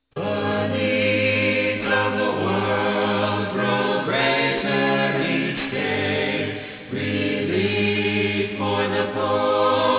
Soprano
Altos
Tenor
Bass